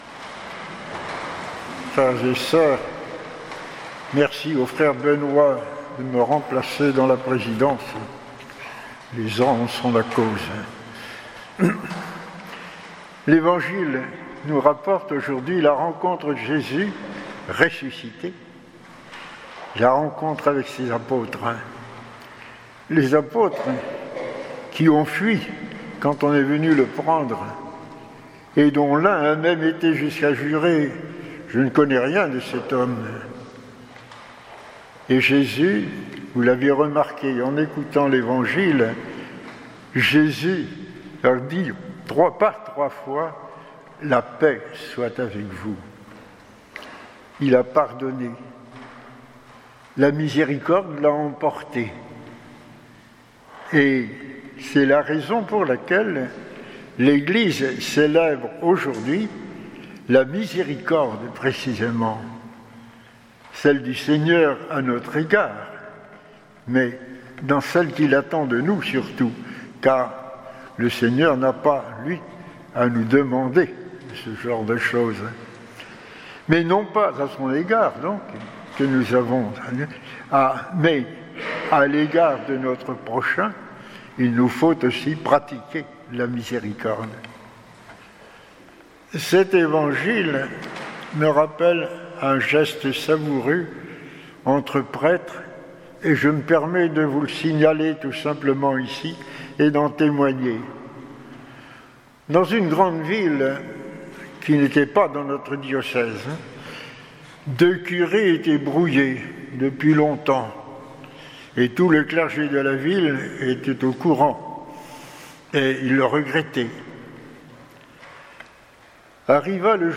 Homélie du 2ème dimanche de Pâques